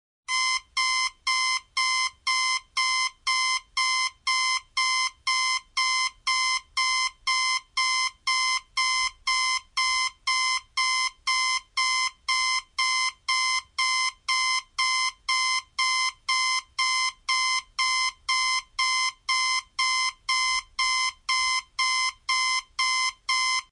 数字闹钟的声音效果
描述：早上闹钟嗡嗡声。
Tag: 嗡嗡声 嗡嗡声 蜂鸣声 蜂鸣声 早上 数字 闹钟 时钟 时间间隔